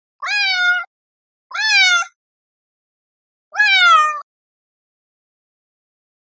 可愛らしい猫の鳴き声 着信音の試聴とダウンロード